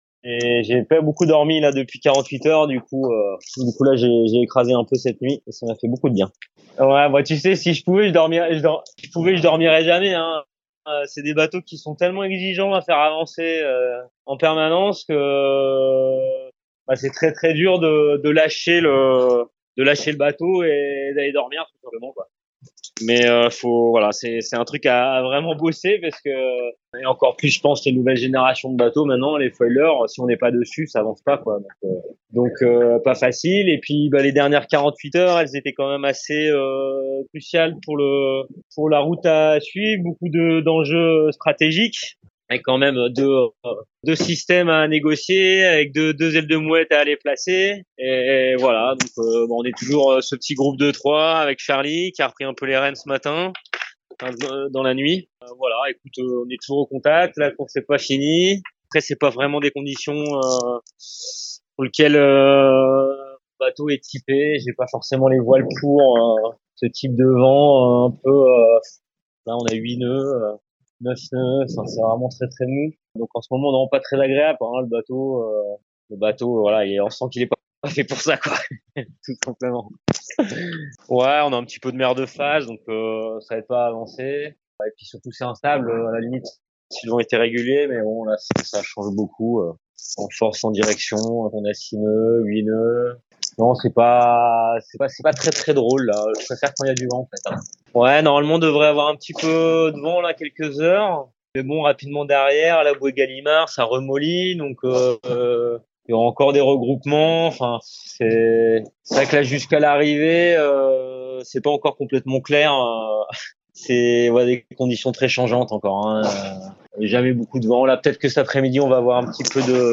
Allô la mer ? Isabelle Joschke (MASCF) et Thomas Ruyant (LinkedOut)